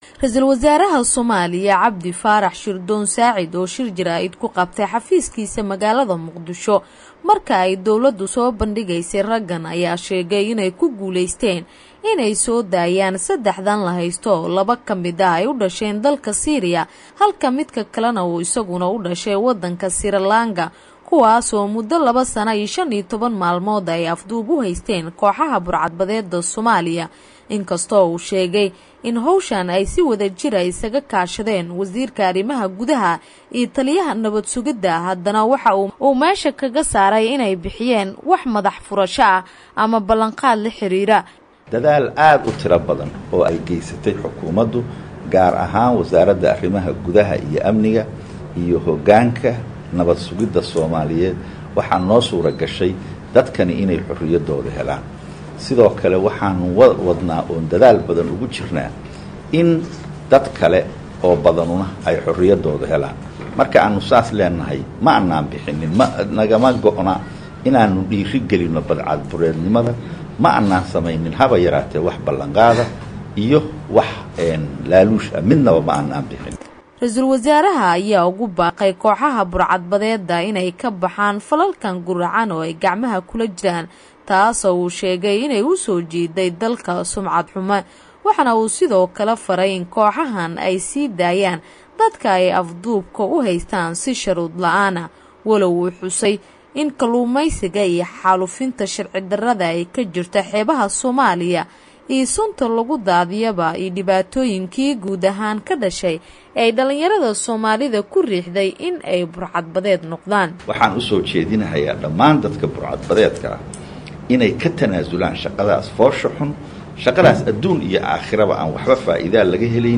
Halkan ka dhageyso warbixinta La heystayaasha la soo furtay